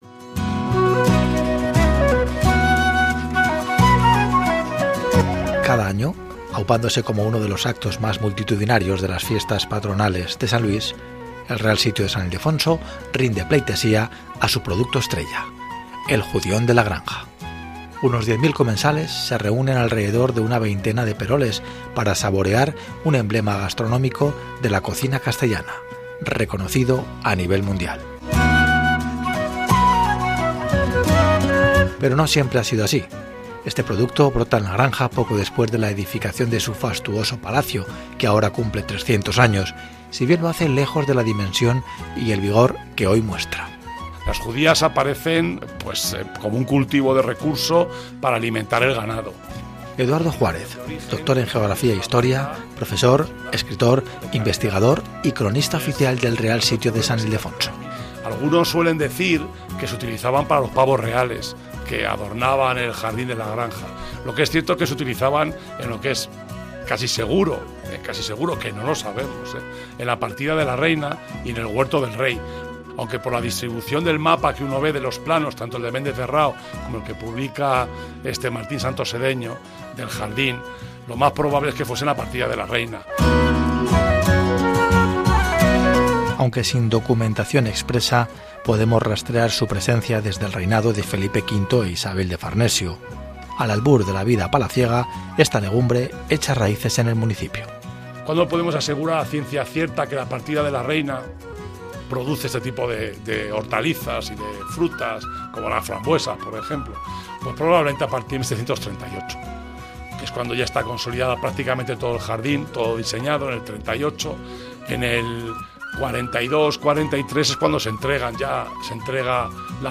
ENTREVISTA ONDA CERO SEGOVIA